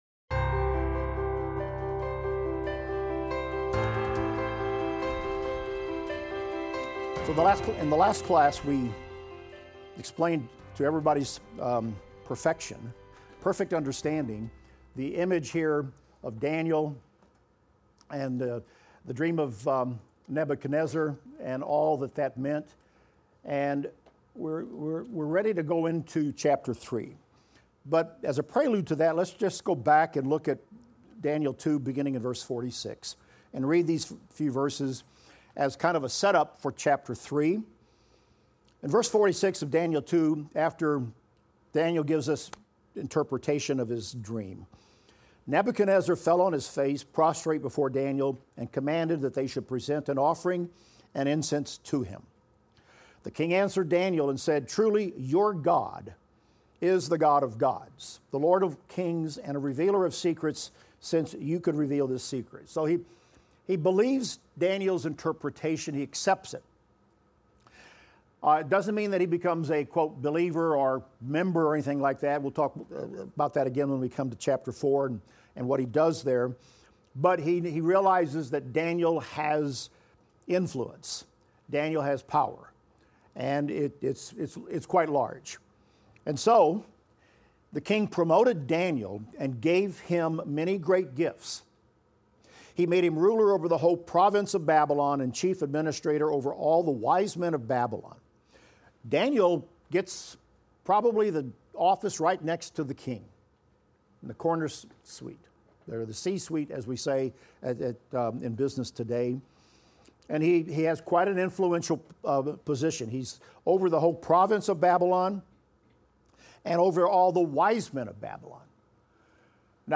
Daniel - Lecture 6 - audio.mp3